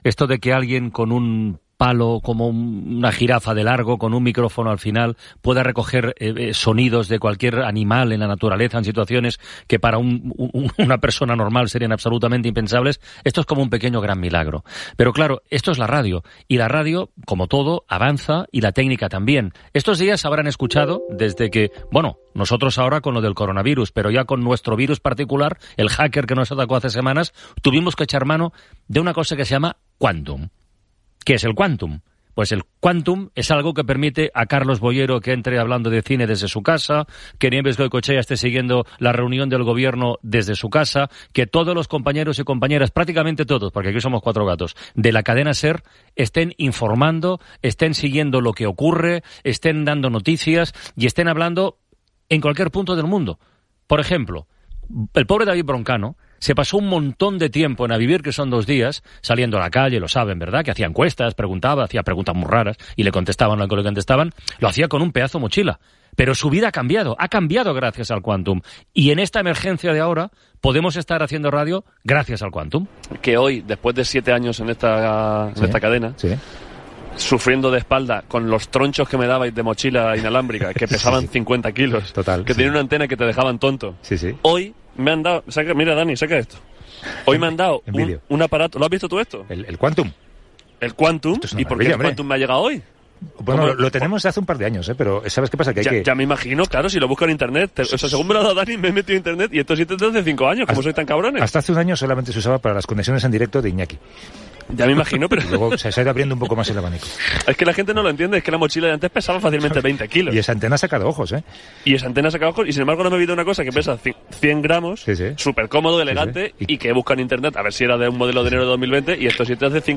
Espai fet durant el confinament de la pandèmia de la Covid 19.
Entreteniment